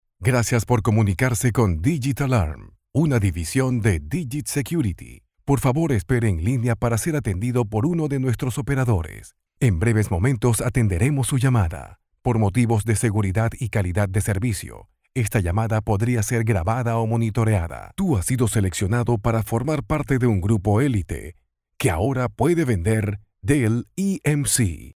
Kein Dialekt
Sprechprobe: Sonstiges (Muttersprache):
Voice over, professional speaker for more than 20 years, own recording studio and availability 24/7. Commercial, IVR, audio books, corporate. documentaries ...